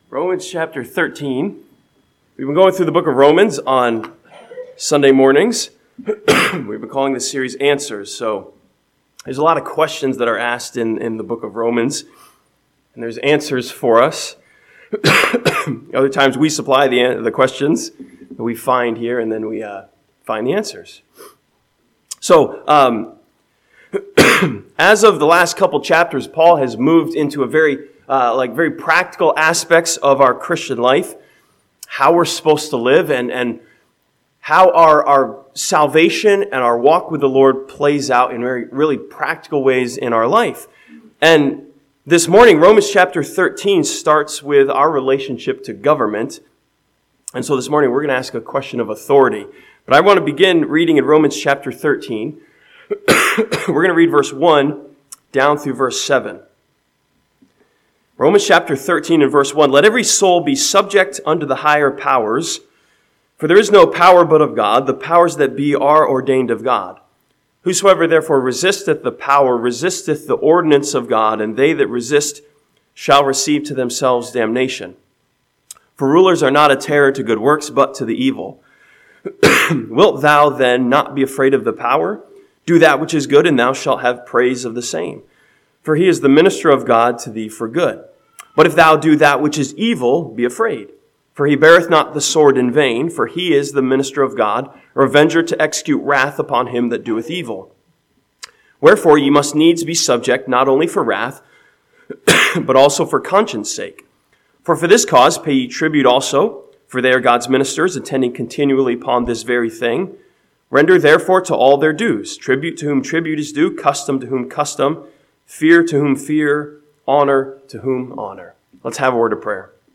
This sermon from Romans chapter 13 studies the question, "who is in charge?" and find the answer to this question of authority.